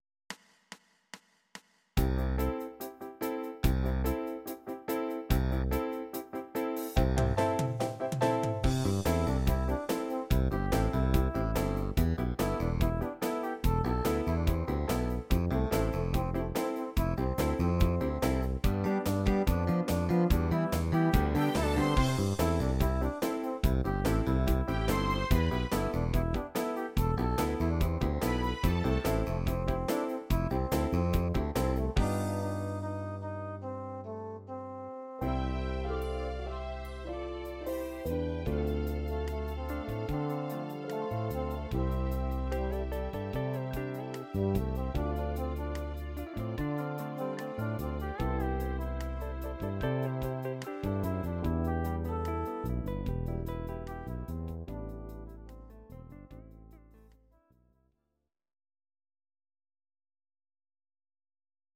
These are MP3 versions of our MIDI file catalogue.
Please note: no vocals and no karaoke included.
Your-Mix: Country (821)